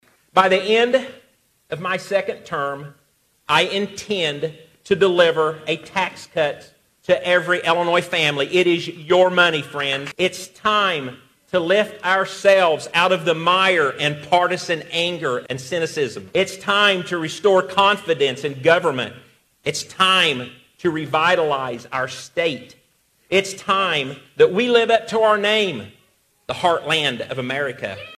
Bailey made the announcement Monday alongside family and friends at the Thelma Keller Convention Center in Effingham.
Bailey told the crowd, Illinois has unlimited potential, but the state needs a governor who’s willing to listen and lead.